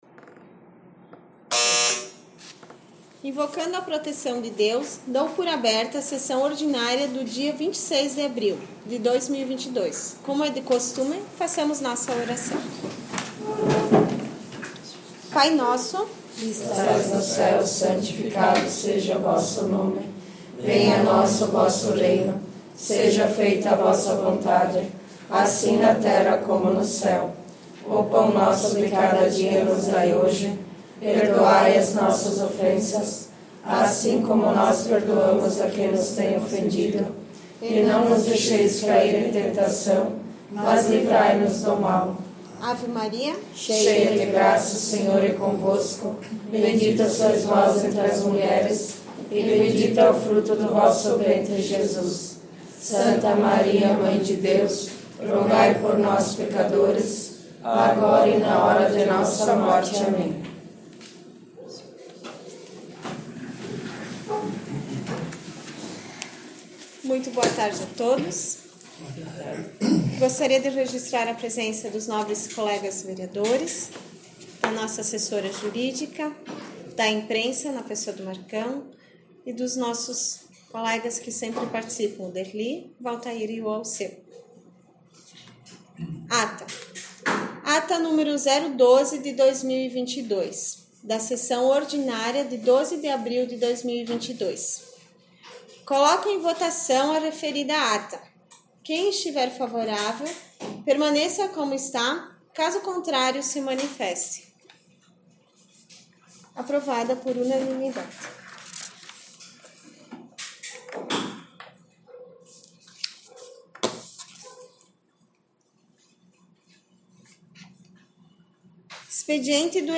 10 - Sessão Ordinária 26 abril 2022 — Câmara Municipal de Boa Vista do Sul
10 - Sessão Ordinária 26 abril 2022